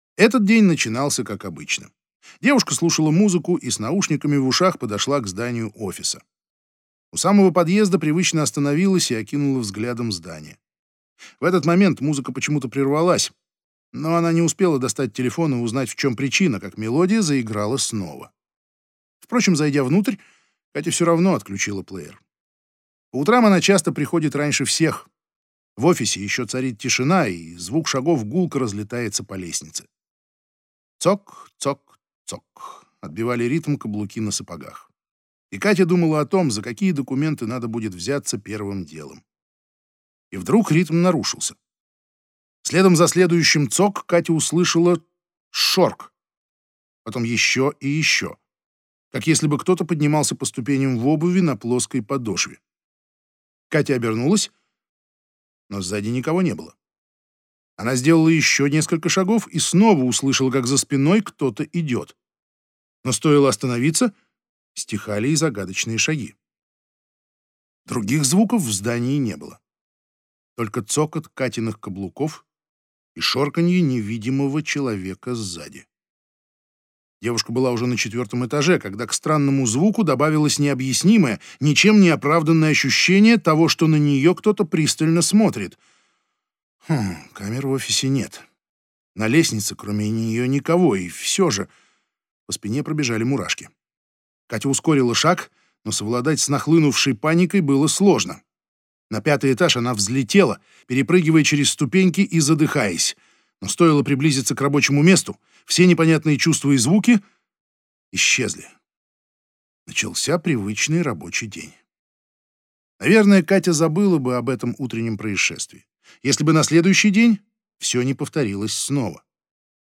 Аудиокнига Будет страшно. Дом с привидениями | Библиотека аудиокниг